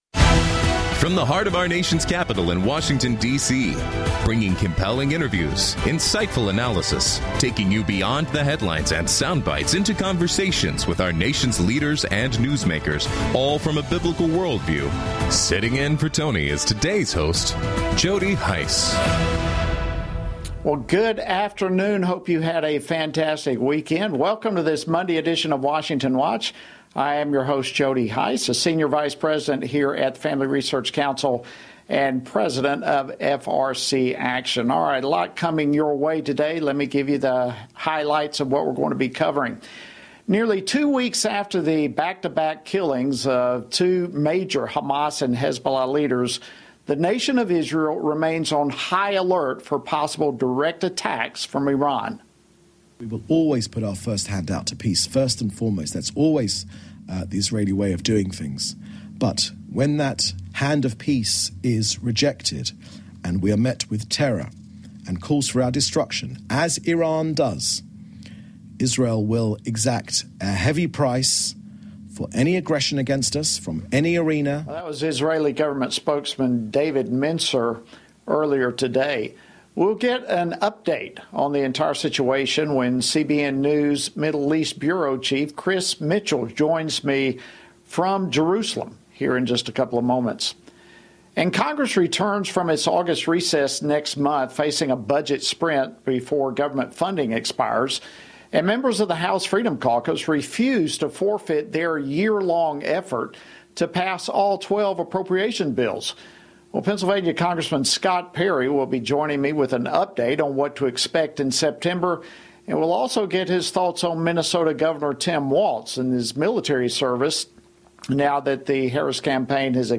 Scott Perry, U.S. Representative for Pennsylvania’s 10th District, analyzes the threat Iran poses to Israel and offers his thoughts as a former Brigadier General on the controversy surrounding Minnesota Governor and Democratic VP candidate Tim Walz’s military service.